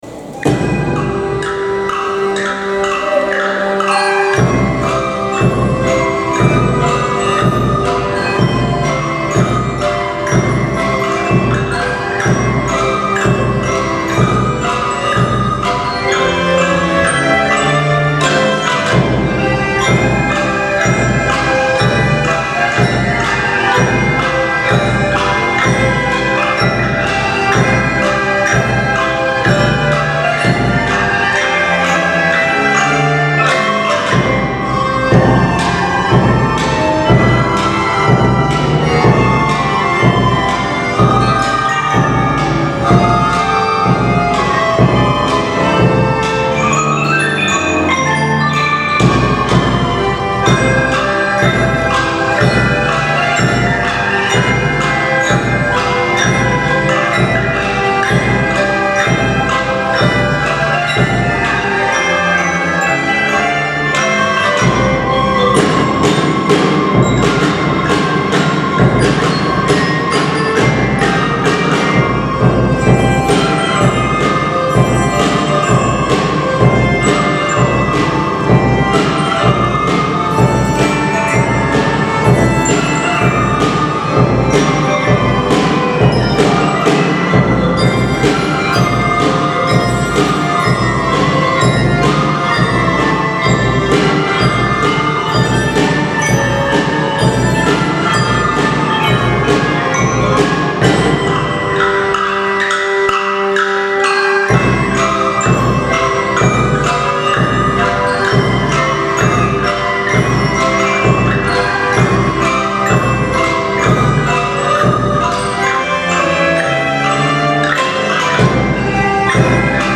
３年生は４月から始めたリコーダーで合奏をつくります。
４年生はいろいろな楽器で時計のチクタクの音や目覚ましの音、時計が壊れる音などたくさんの音を表現しました。